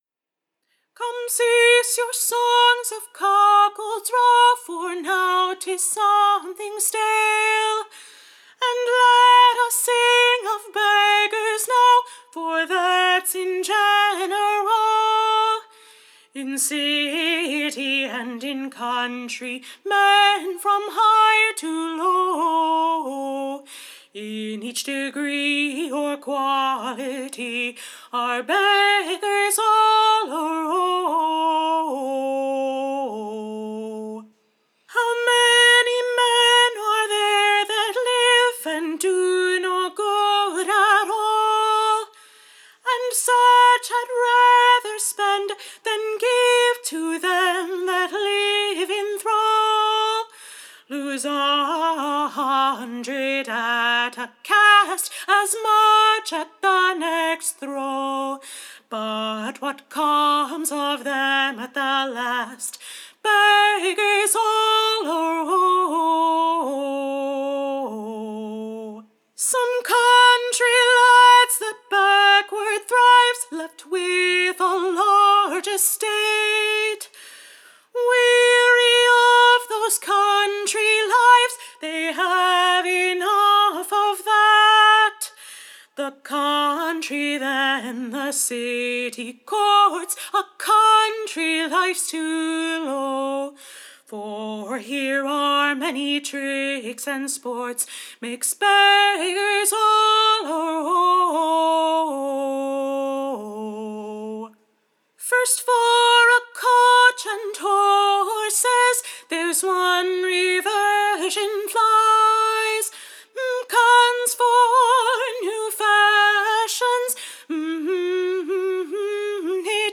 Recording Information Ballad Title A pleasant new song that plainely doth show, / that al are Beggers, both high and low, / A meane estate let none despise: / for tis not Money that makes a man wise. Tune Imprint To the tune of Cuckolds all a row.